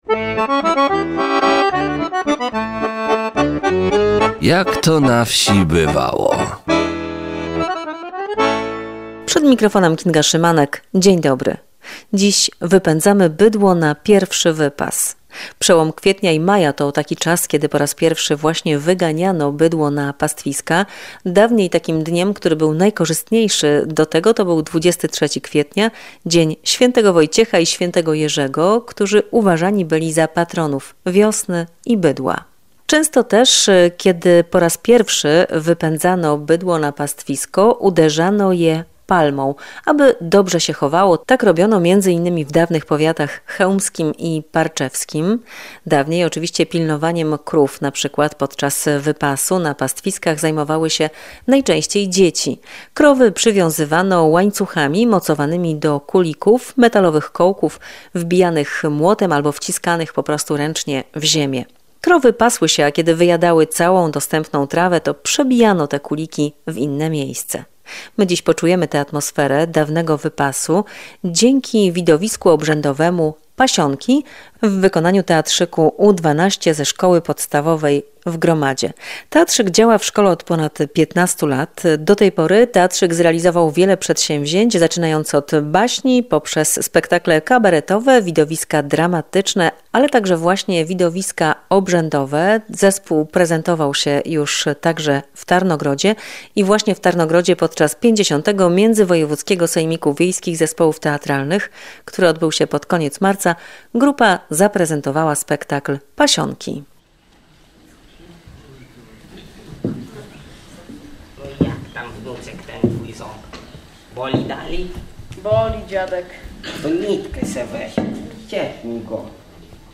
Dziś zapraszamy na widowisko obrzędowe „Pasionki” w wykonaniu Teatrzyku U-12 ze Szkoły Podstawowej w Gromadzie (pow. biłgorajski).